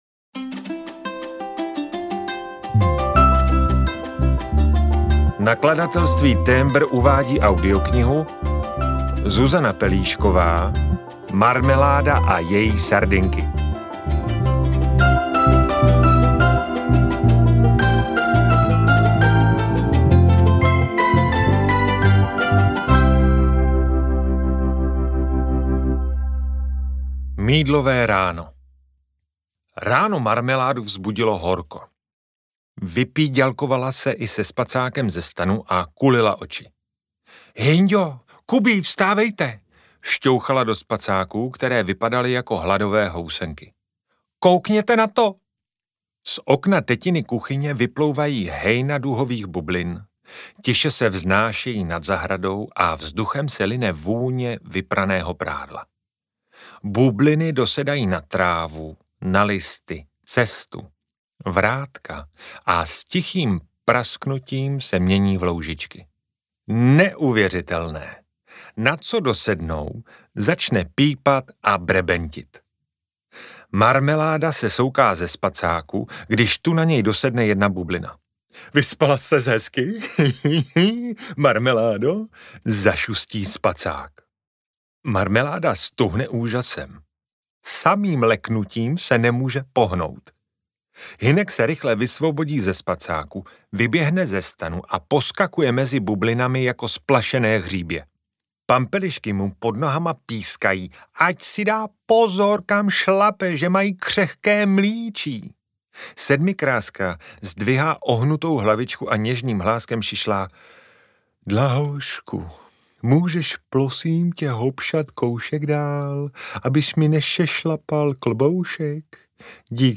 Marmeláda a její sardinky audiokniha
Ukázka z knihy